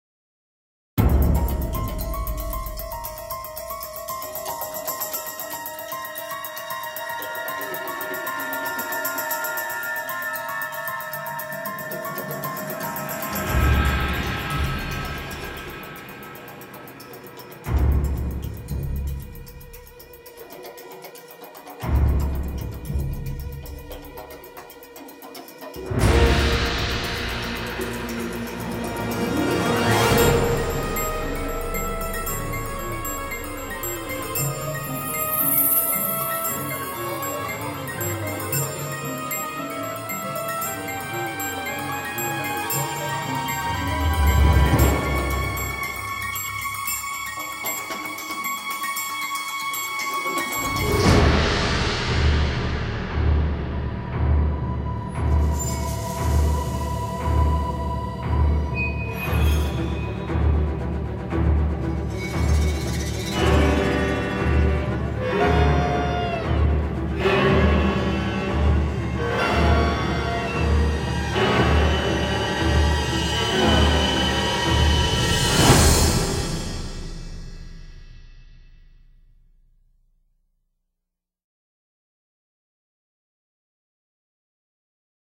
Pas De Paroles